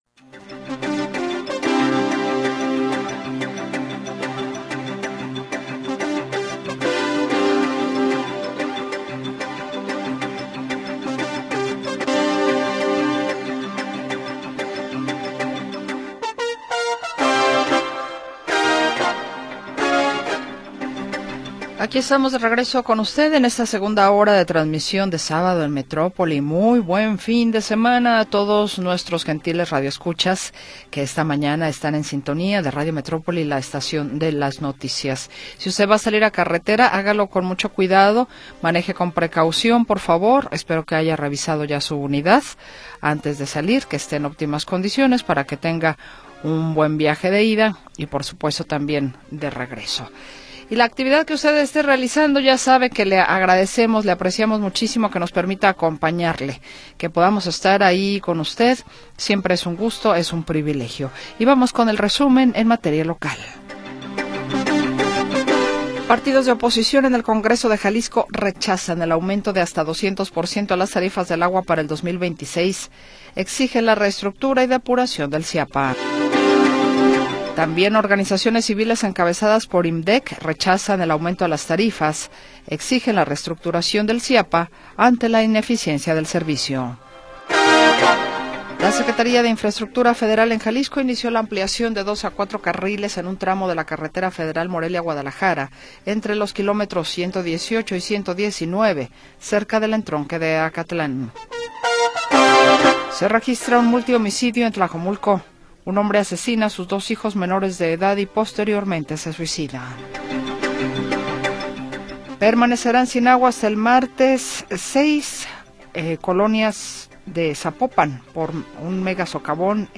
26 de Julio de 2025 audio Noticias y entrevistas sobre sucesos del momento